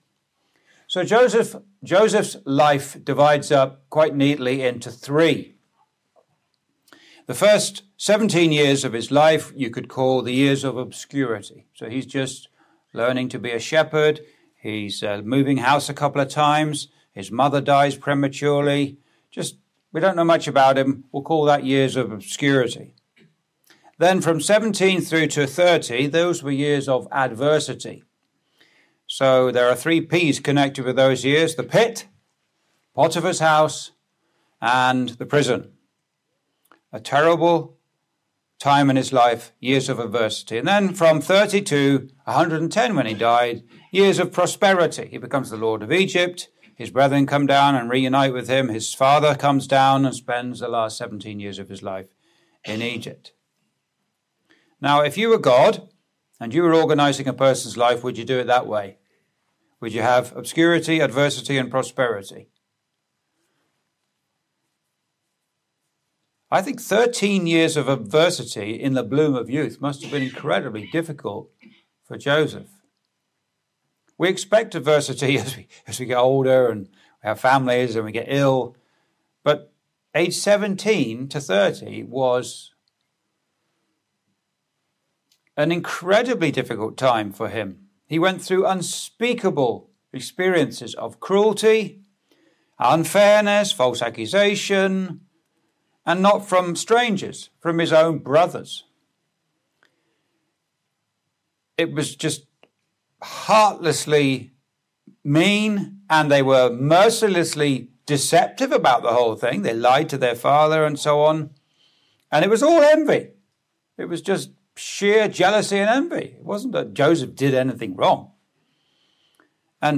(Recorded in The Malden Road Gospel Hall, Windsor, ON, Canada on 11th Jan 2026) Complete series: Nehemiah - Building for God (The Sword and the Trowel) Joseph
Video Ministry